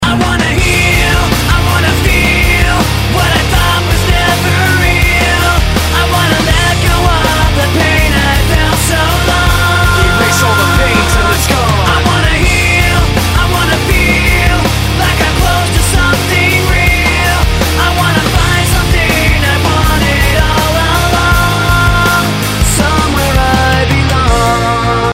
Категория: Рок-музыка